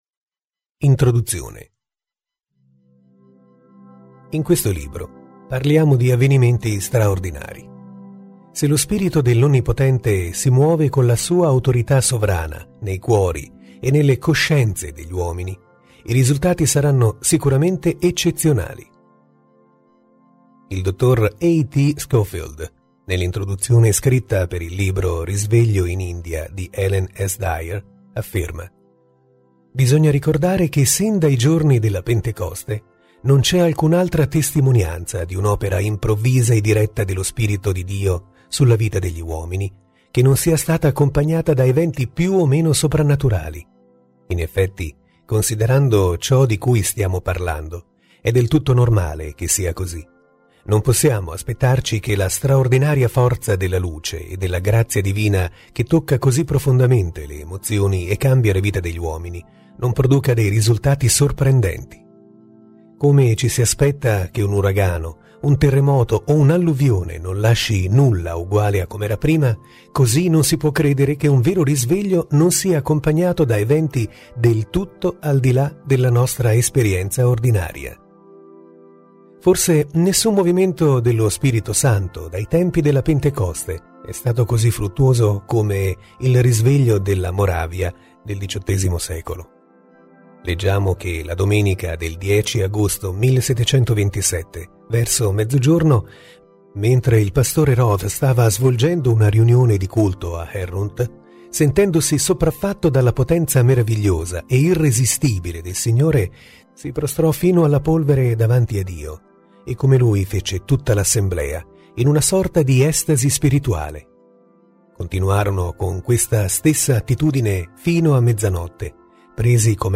Lettura integrale MP3